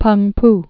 (pŭngp)